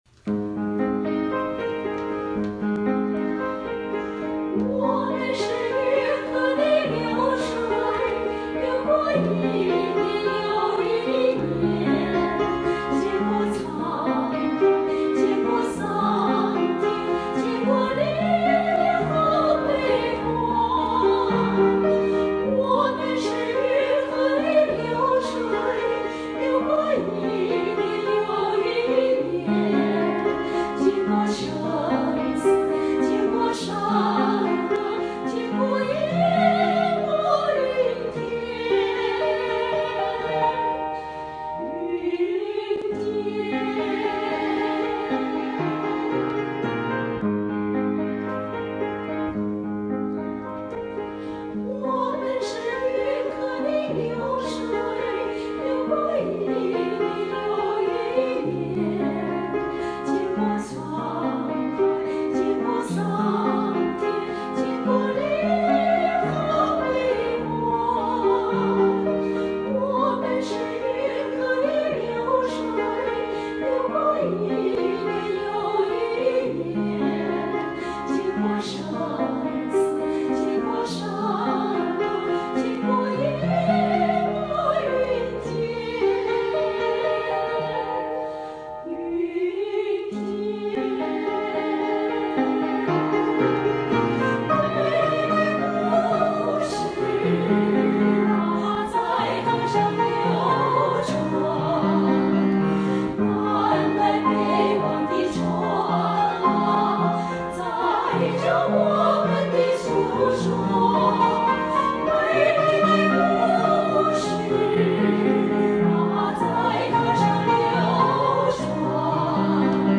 秋韵组合：我们是运河的流水 现场录音 元宵节聚会
献上一首元宵节聚会的现场录音的一首歌， 这首歌来自歌剧“运河遥”选段。
这段女声小合唱原唱非常优美动听， 由于是新作品， 没有伴奏 ， 我们有幸一位高资深的钢琴老师给我们伴奏， 大家在聚会上唱得很兴奋激情。